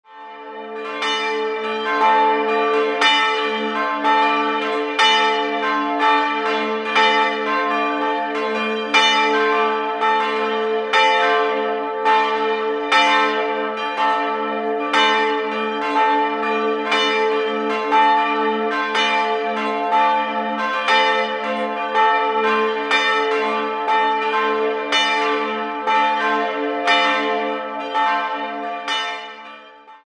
3-stimmiges TeDeum-Geläute: a'-c''-d'' Die Glocken a' und d'' stammen von Friedrich Wilhelm Schilling (Heidelberg) aus dem Jahr 1958 und wiegen 410 bzw. 193 kg, die mittlere Glocke wurde im Jahr 1877 gegossen.